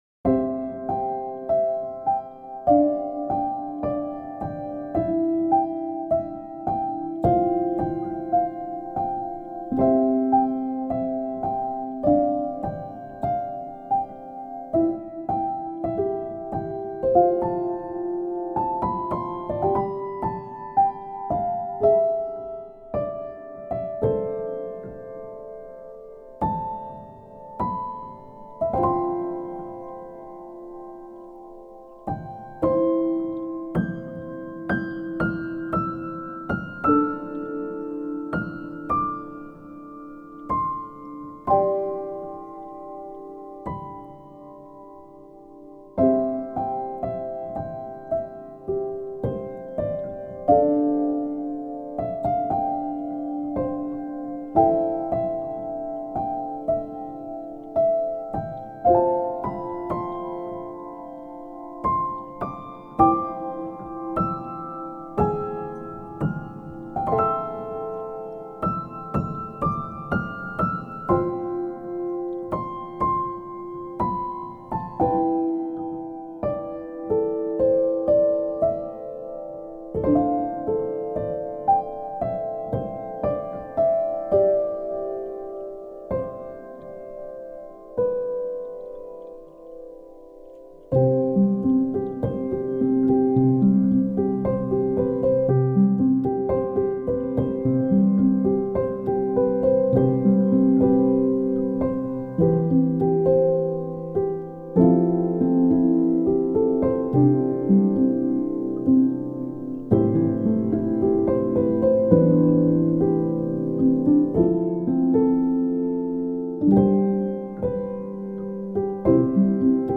ピアノ
穏やか